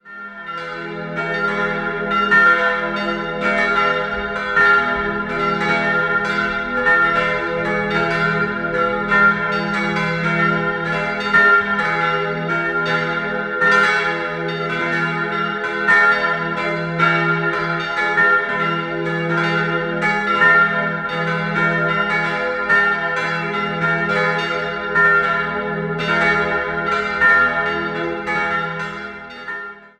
Seit 1844 ist Hellbühl eine eigenständige Pfarrei. 4-stimmiges Geläut: f'-a'-c''-f'' Die beiden größeren Glocken wurden 1834, die kleinen 1840 von Rüetschi gegossen.